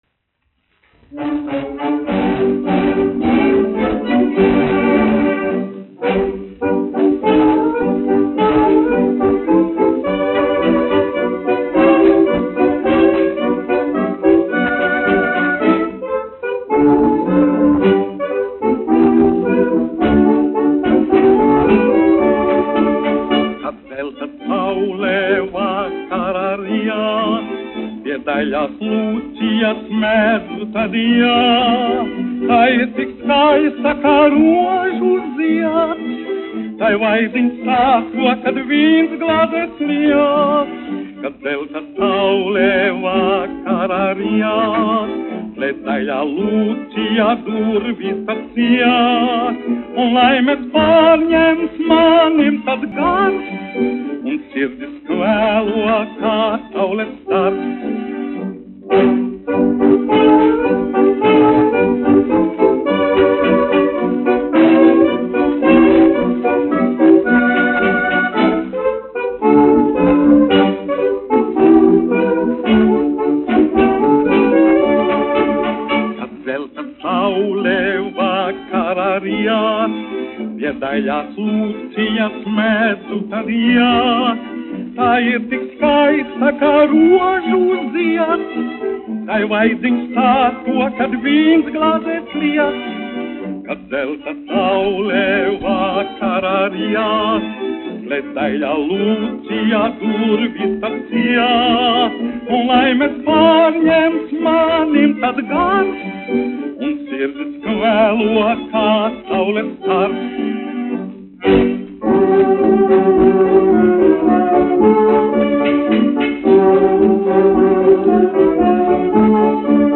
1 skpl. : analogs, 78 apgr/min, mono ; 25 cm
Populārā mūzika
Fokstroti
Latvijas vēsturiskie šellaka skaņuplašu ieraksti (Kolekcija)